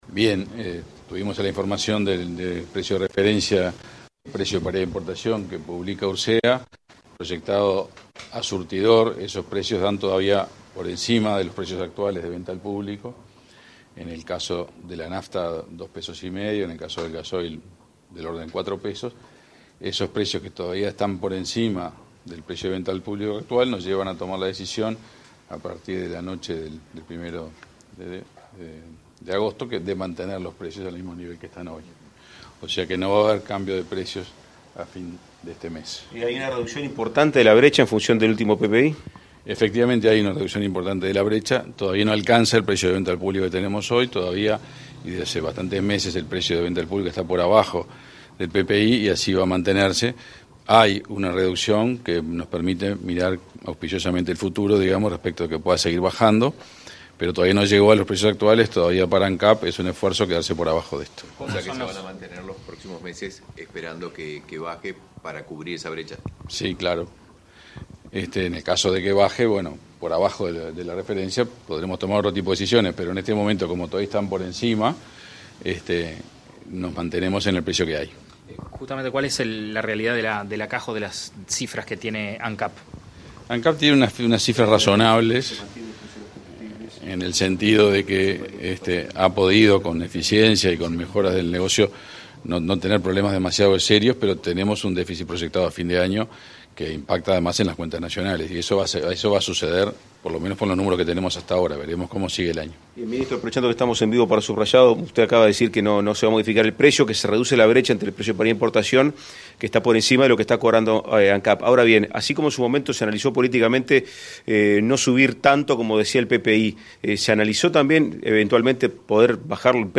Declaraciones del ministro de Industria, Omar Paganini
El ministro de Industria, Omar Paganini, dialogó con la prensa acerca del ajuste del precio del combustible correspondiente al próximo mes.